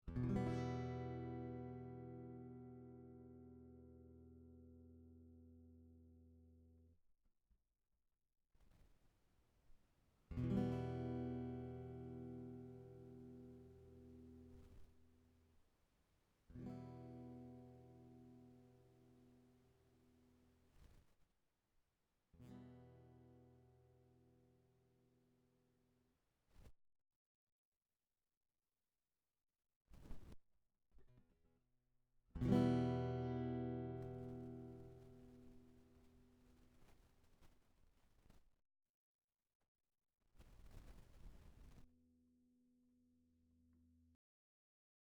Fishman Ysis+ (Presys II) defekt? (starkes Rauschen bei Vol<100%)
Manchmal beim bloßen Berühren des Vol.Knopfes lautes Krachen...